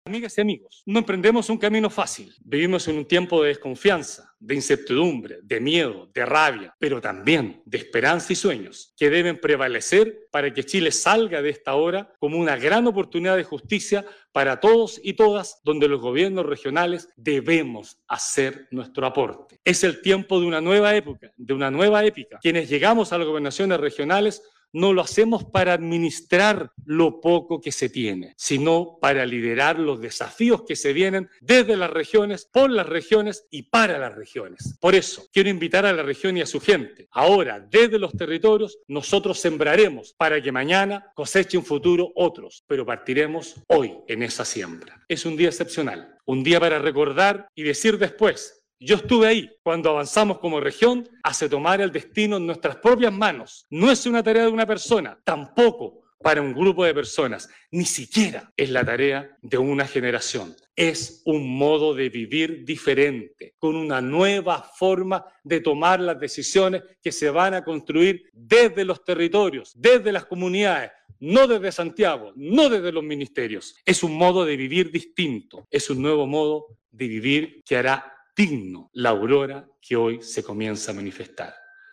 La nueva autoridad regional señaló que no se emprende un camino fácil: